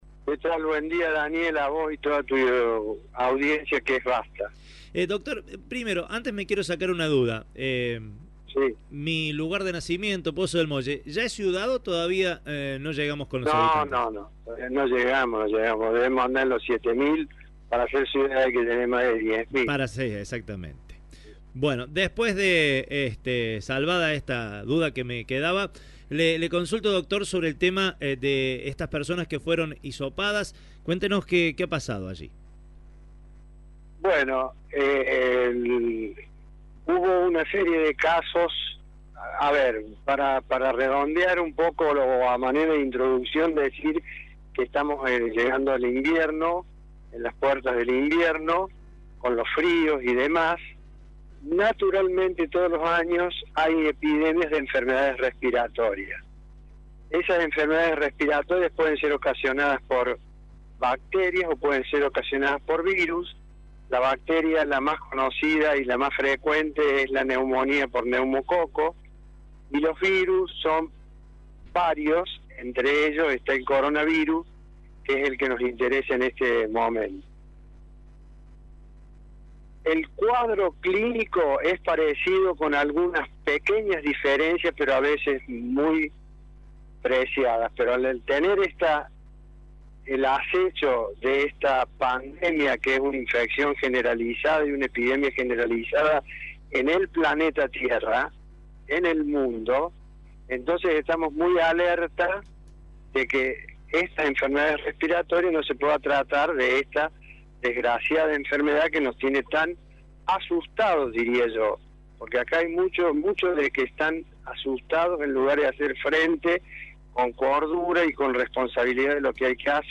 El intendente de Pozo del Molle, el Dr. Jorge Roland habló con Radio show.